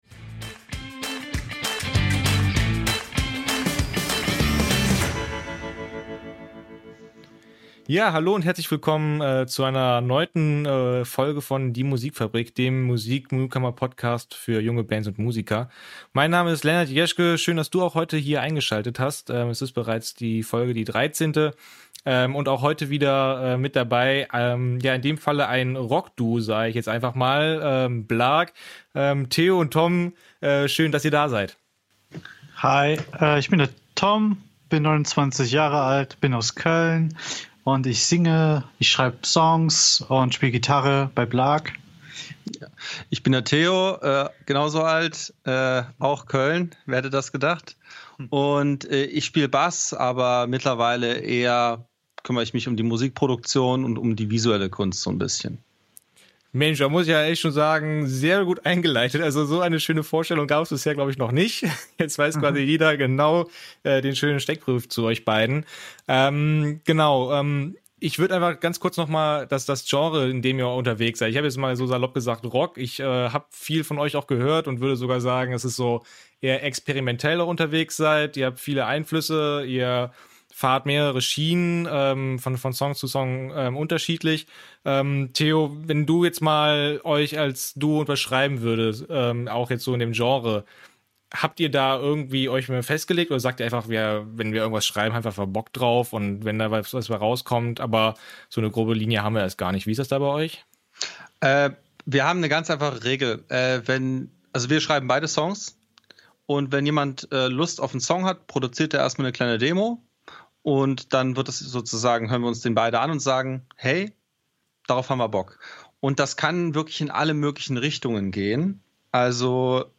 September 2021 Nächste Episode download Beschreibung Teilen Abonnieren Die Musikfabrik - Der Musik-Newcomer Podcast: Es sind mal wieder Gäste aus Köln da.